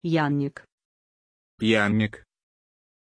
Aussprache von Yannik
pronunciation-yannik-ru.mp3